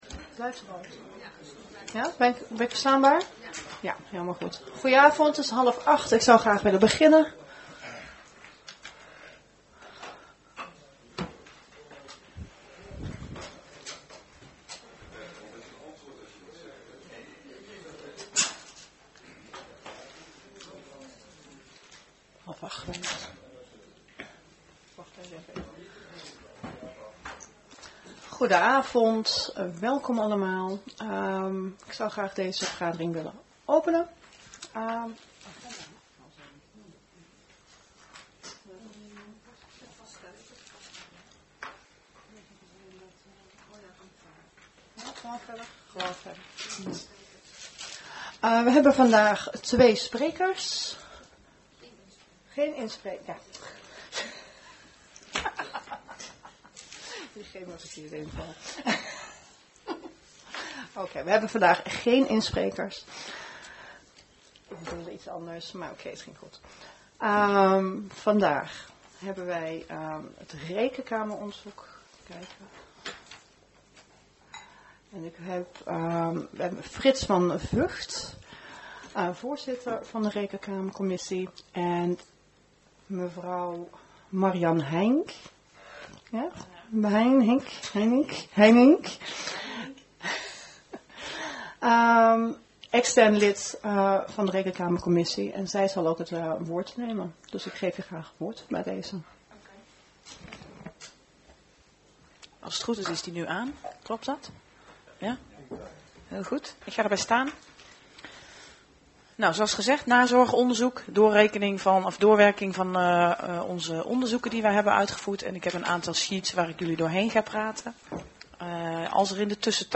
Locatie gemeentehuis Elst Voorzitter mevr. E. Patelias-Pieks Toelichting Informatiebijeenkomst rekenkamerrapport "Nazorgonderzoek" Agenda documenten 18-06-25 Opname ciekamer 2.